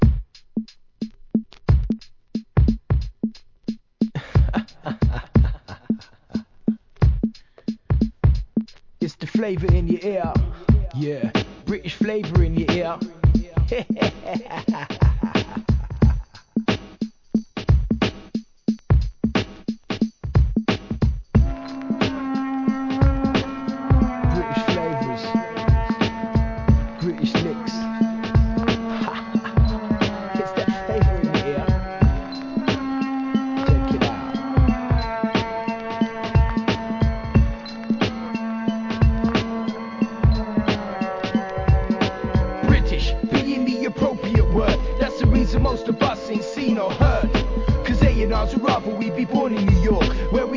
UK HIP HOP